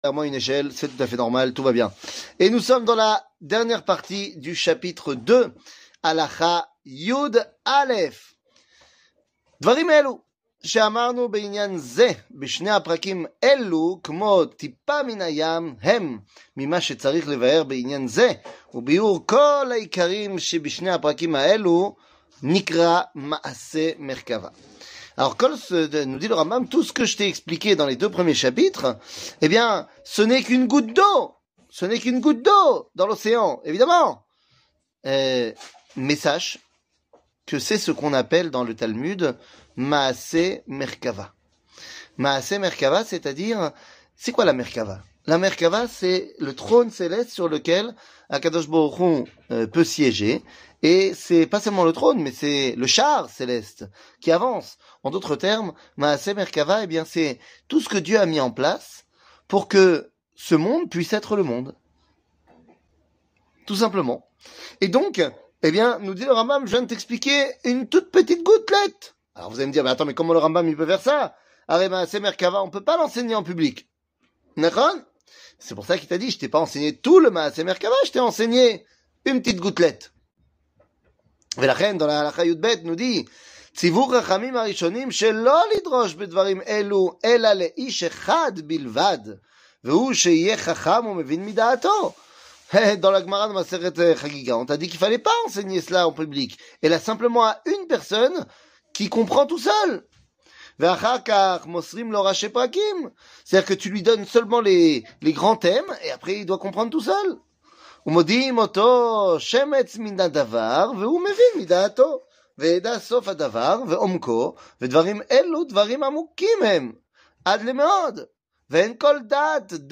שיעור מ 19 פברואר 2024